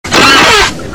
Spongebob Goofy - Bouton d'effet sonore